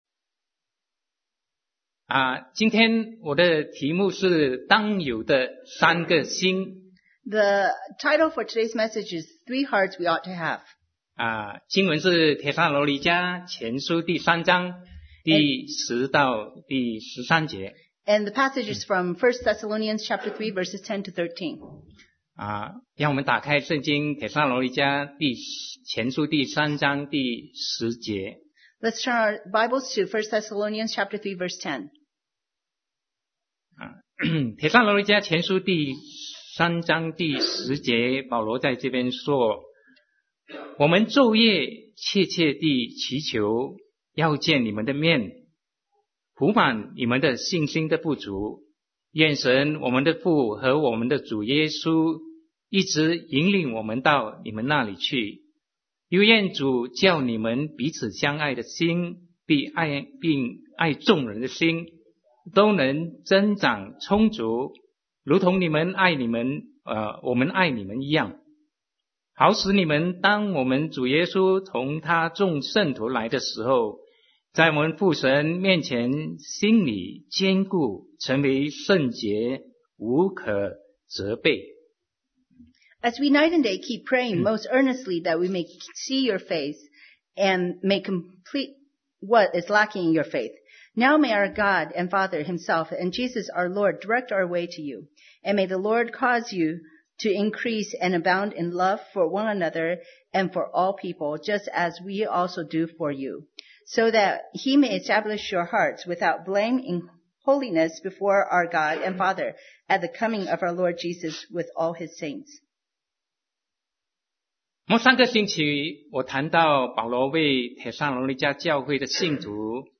Sermon 2017-06-04 Three Hearts We Ought to Have